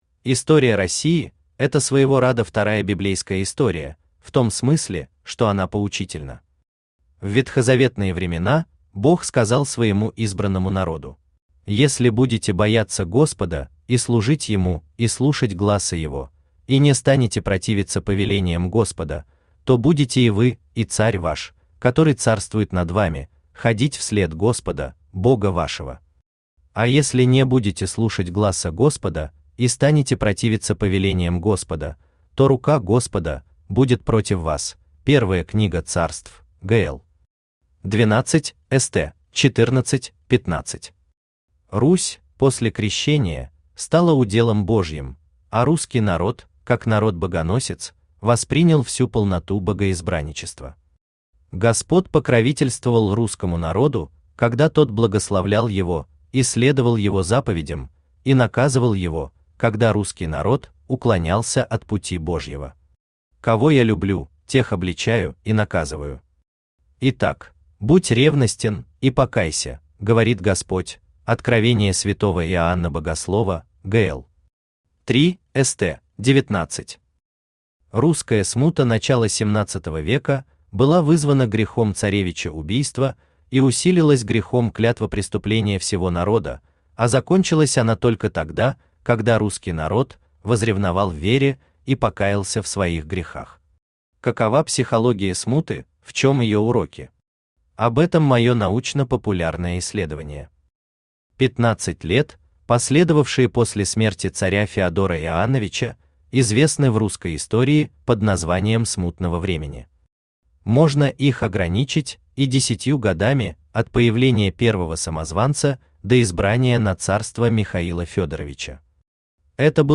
Аудиокнига Уроки Смутного времени | Библиотека аудиокниг
Aудиокнига Уроки Смутного времени Автор Игорь Аркадьевич Родинков Читает аудиокнигу Авточтец ЛитРес.